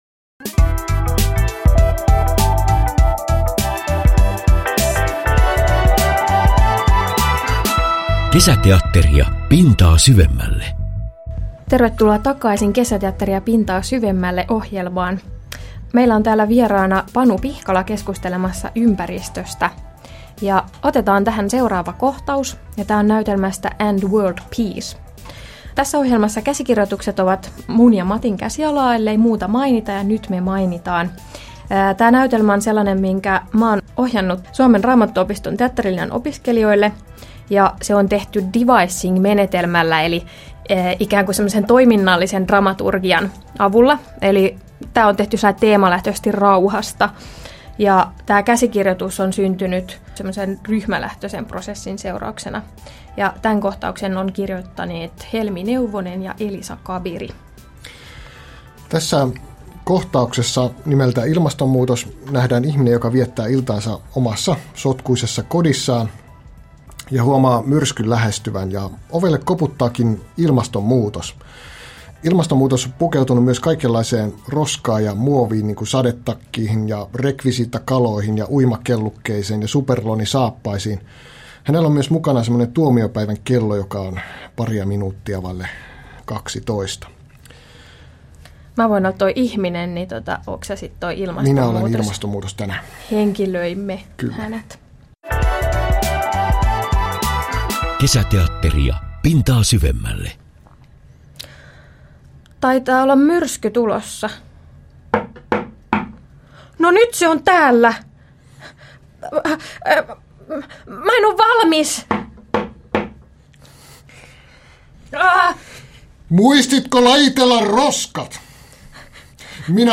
lukevat otteita näytelmistään